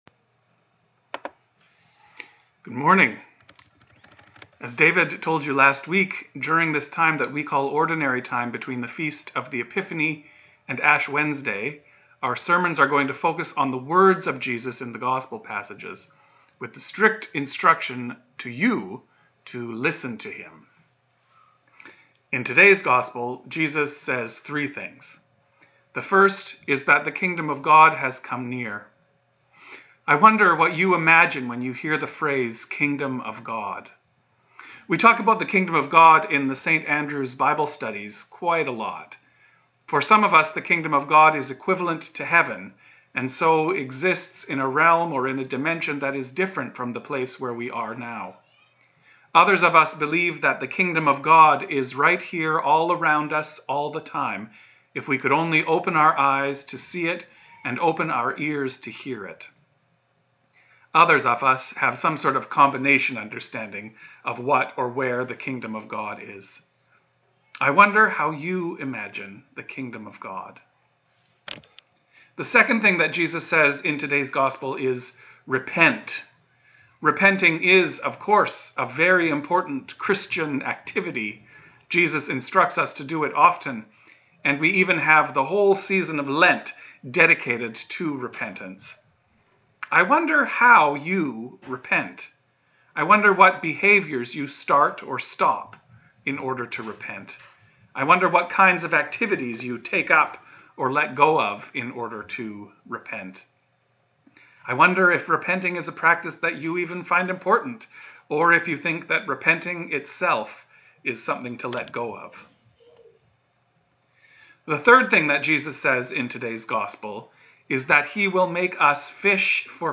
Sermons | St. Dunstan's Anglican